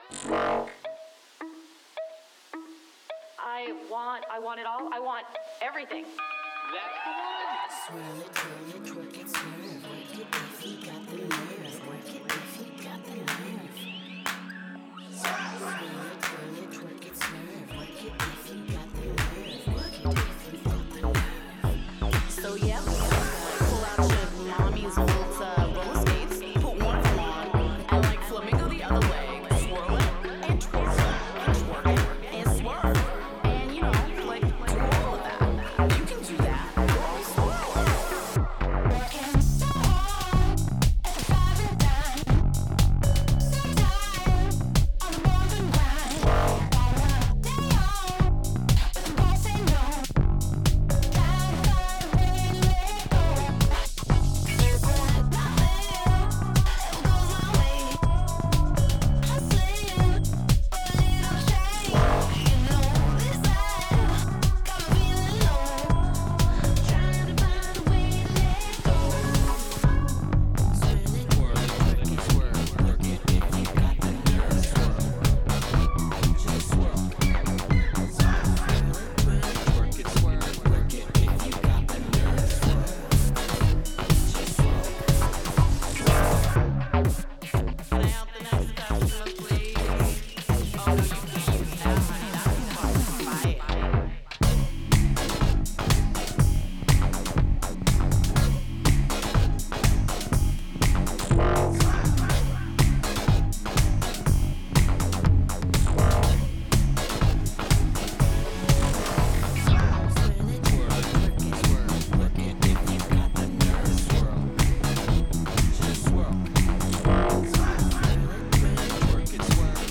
So then, it’s all about Rock. 😉